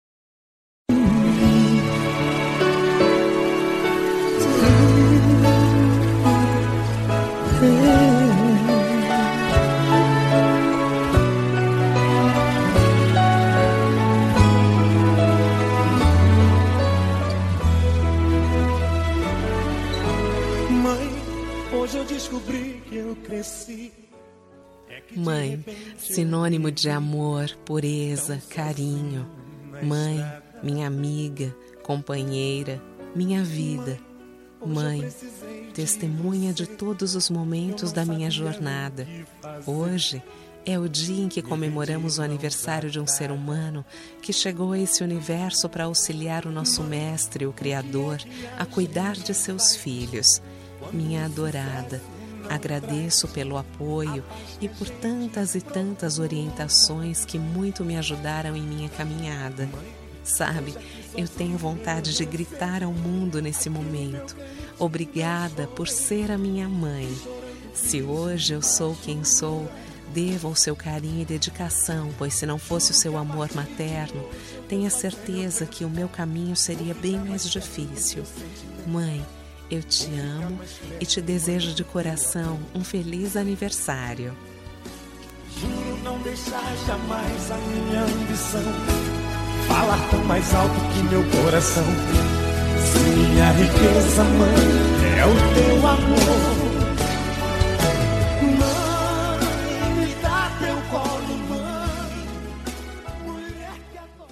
Aniversário de Mãe – Voz Feminina – Cód: 035351